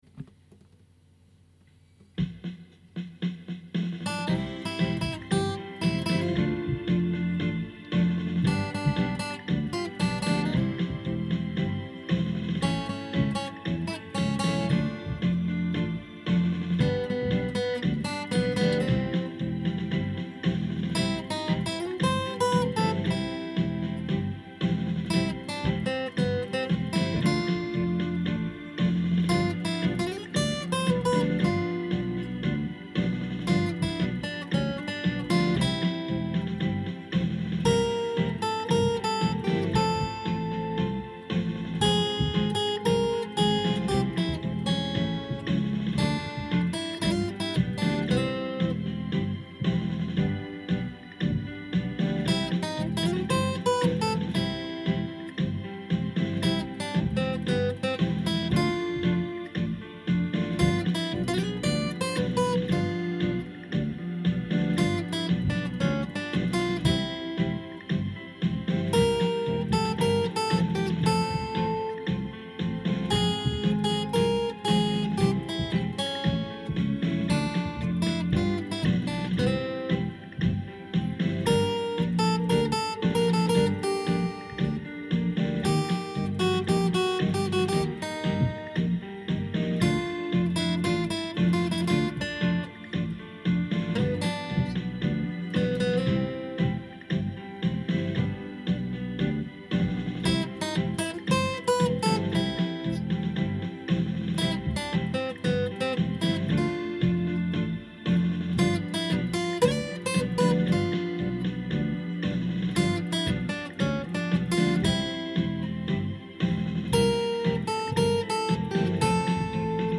TANGO
Tango Guitare